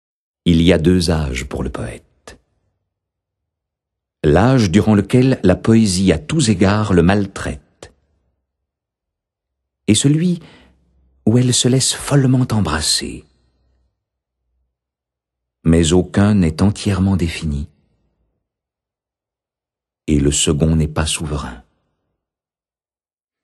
Get £2.08 by recommending this book 🛈 Ce CD vient d’obtenir le Prix de l’Académie Charles Cros, Coup de Coeur 2007 poésie & musique !